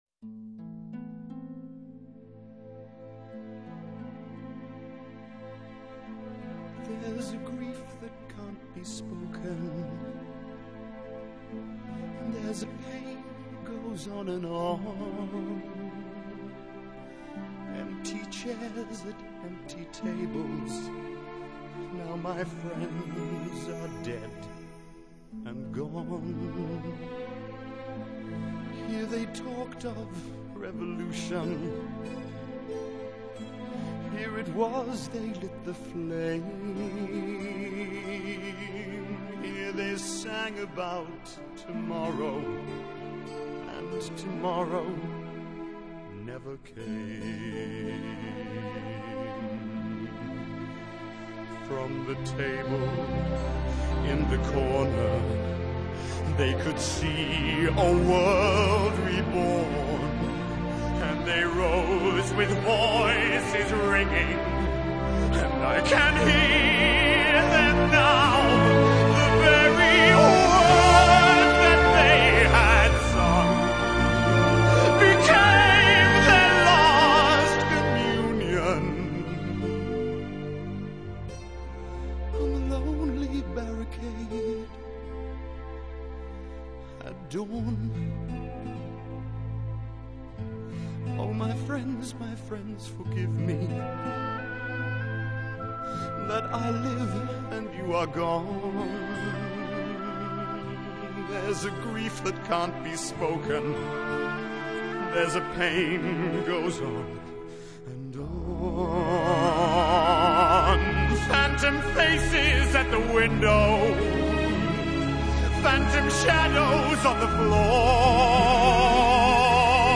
國際版的配樂較雄壯…